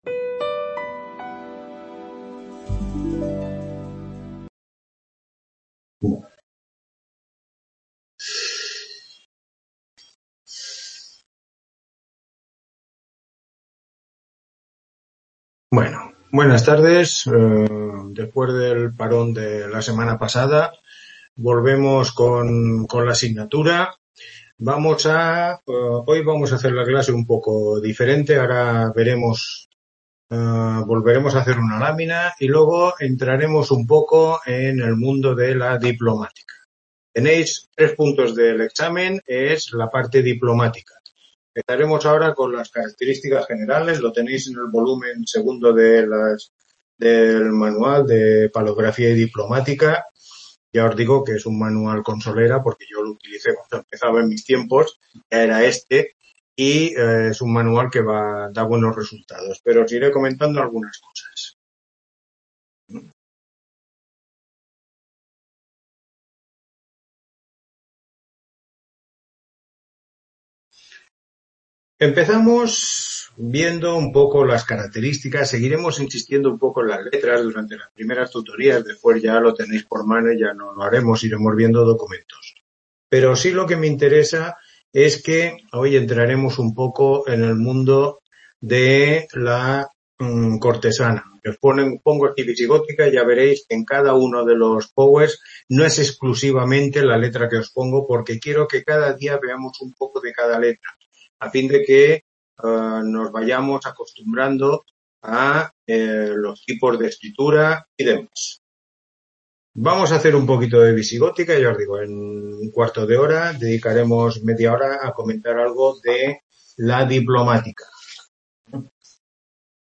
Tutoría 3